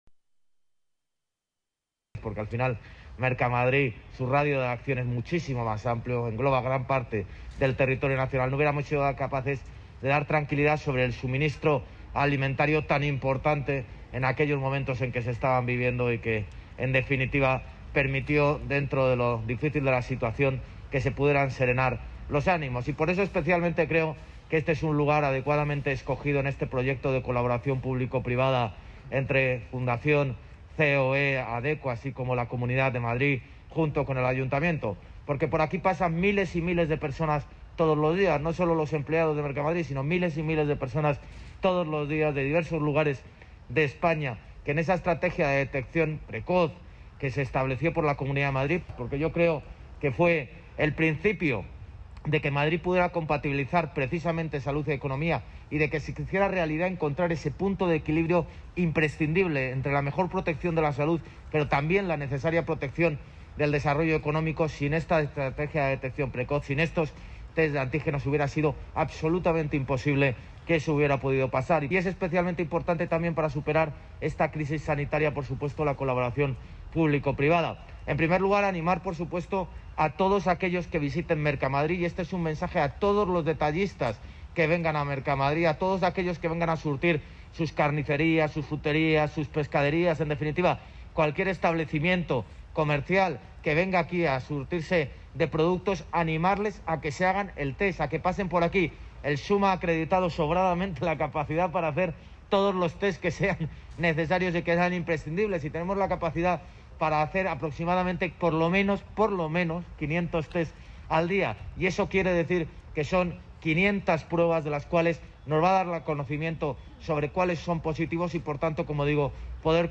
Nueva ventana:José Luis Martínez-Almeida, alcalde de Madrid